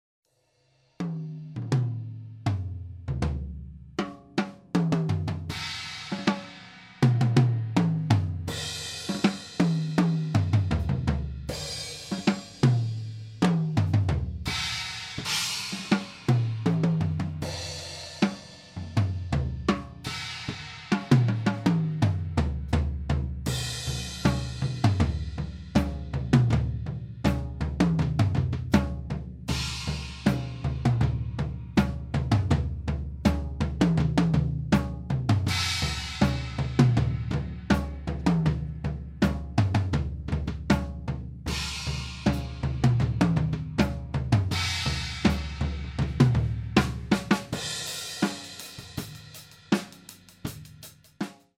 Kein EQ, nur Panning
Im Proberaum habe ich mit einem Behringer xr18 und CakeWalk aufgenommenen.
bei der Tama-Datei klingt alles trockener und dank weniger Anschlagsgeräusch etwas wärmer.